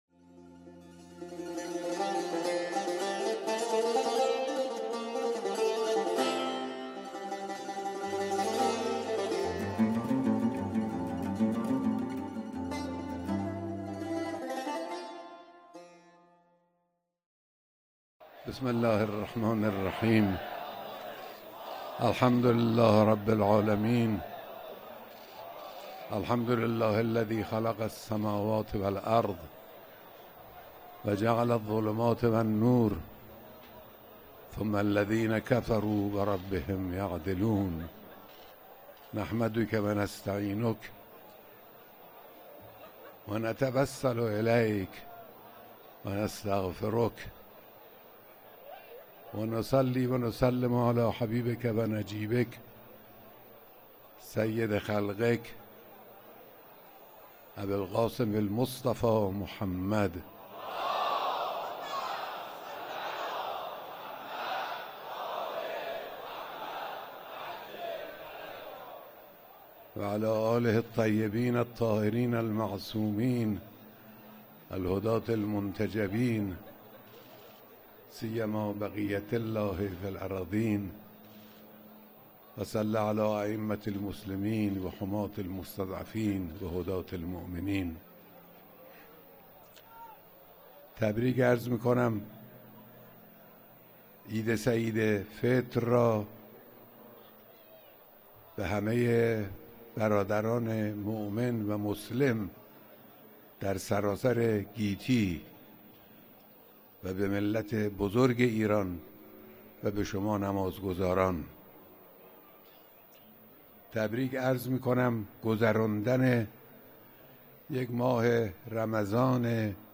خطبه‌های نماز عید فطر